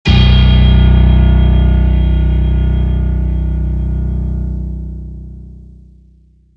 HardAndToughPiano